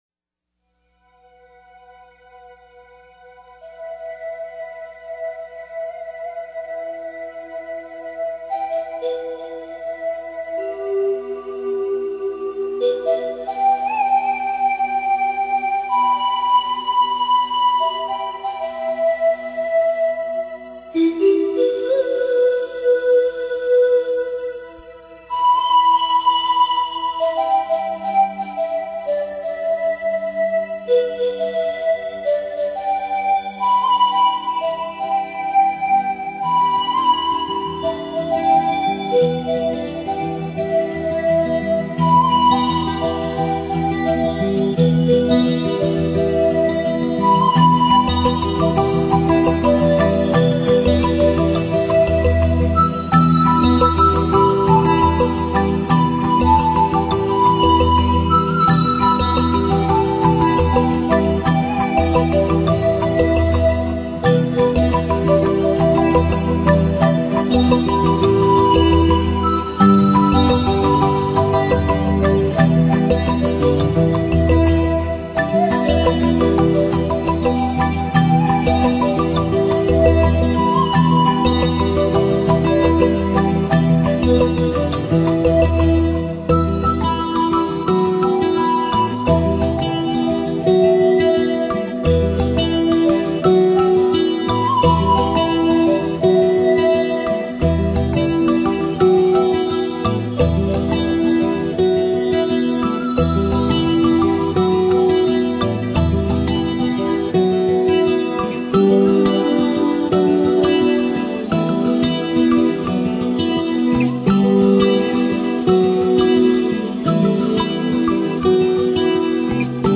风格：New Flamenco
本片结合吉普赛人流浪的情结，加上东方的迷幻多情，使整张专辑营造出一幕幕幽远意象，如泣如诉地演奏出感人动听的旋律。
可惜只有64k品質--只有心領了